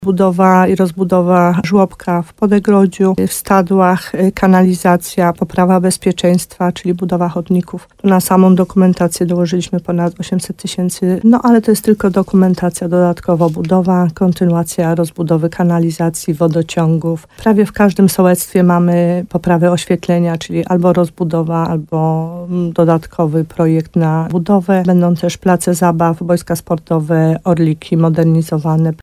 Jak mówi wójt Małgorzata Gromala, część z nich to kontynuacja już rozpoczętych przedsięwzięć.